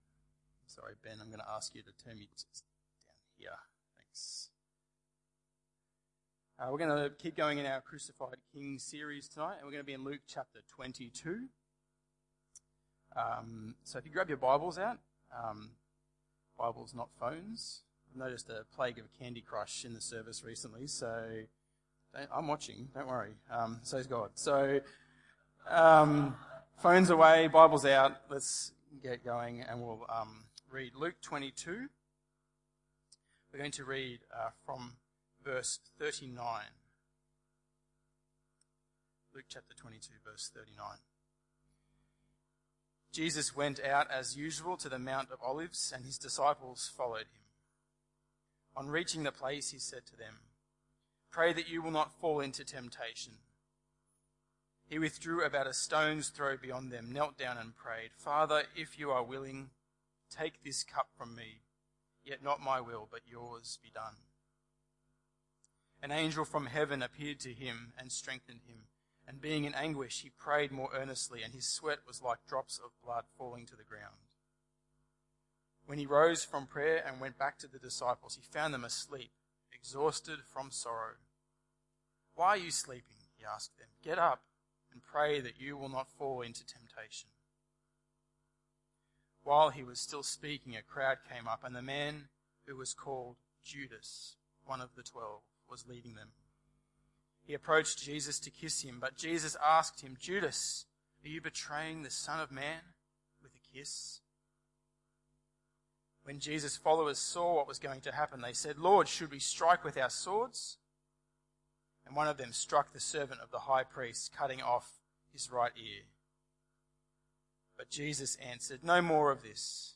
Luke 22:39-65 Tagged with Sunday Evening